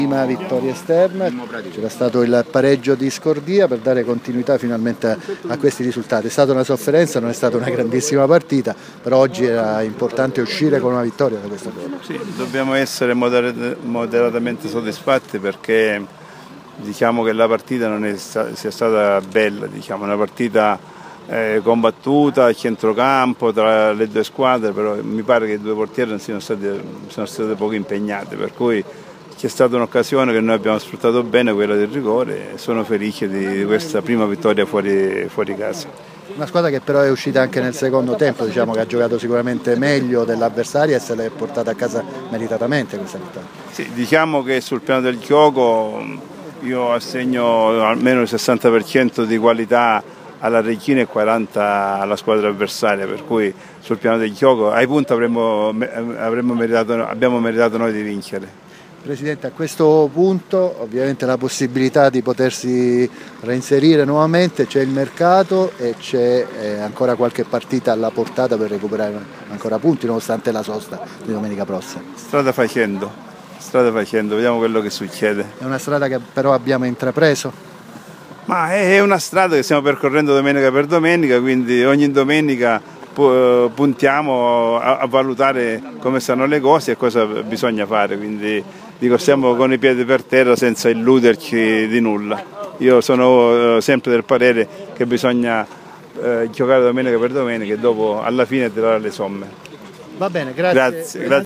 dalla stampa del "Guariglia" di Agropoli.